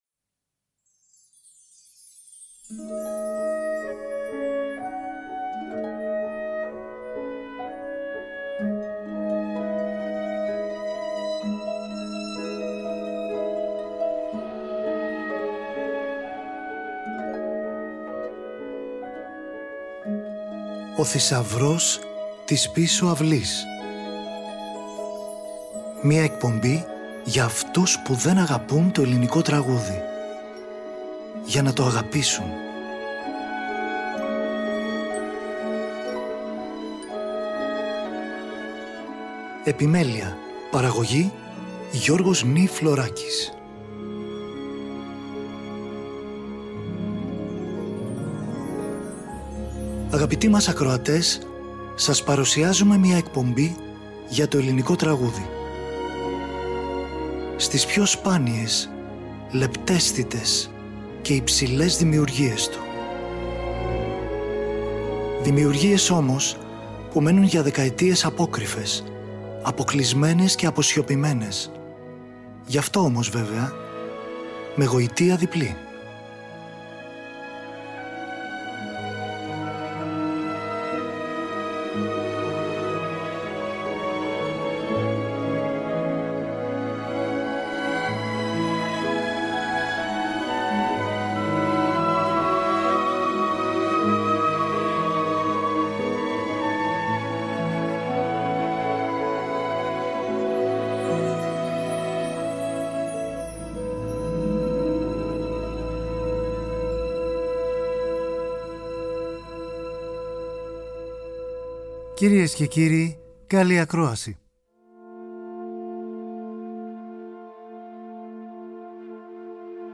κουαρτέτο
τραγούδια και ορχηστρικά